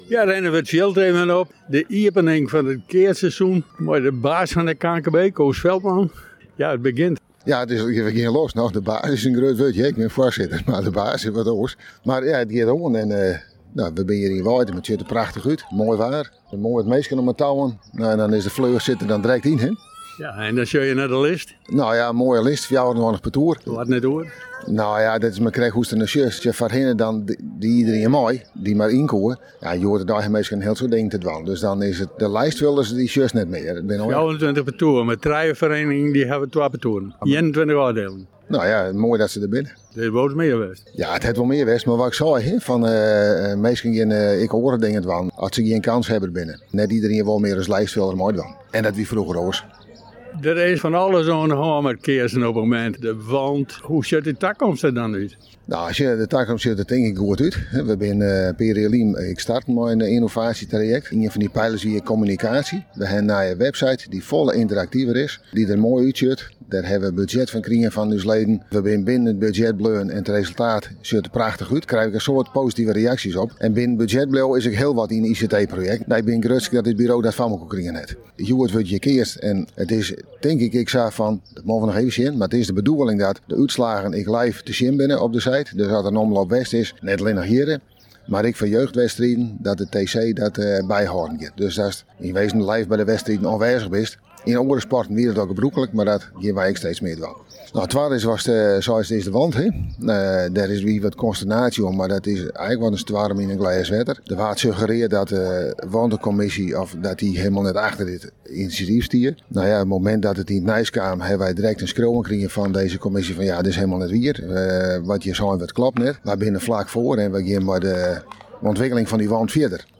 Een gesprek